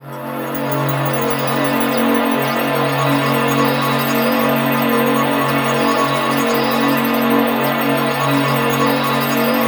DM PAD1-17.wav